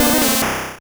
Cri de Mystherbe dans Pokémon Rouge et Bleu.